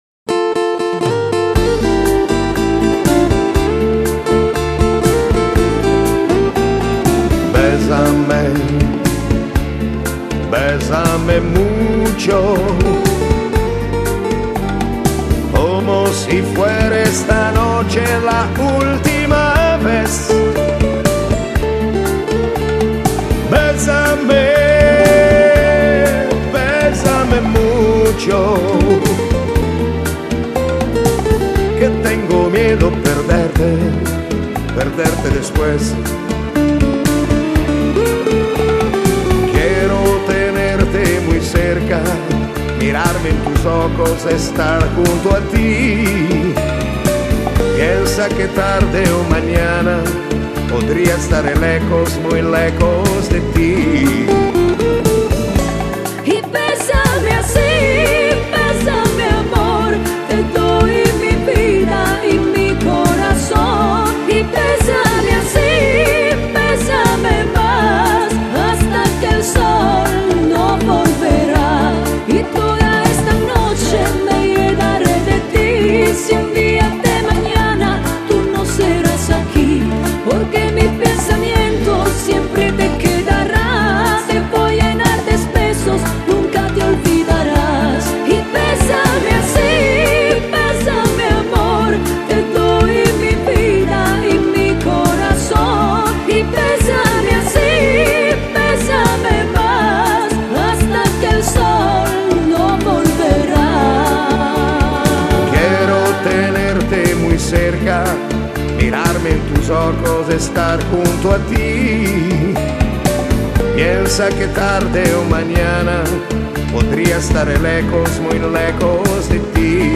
Genere: Bachata